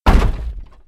Collision.mp3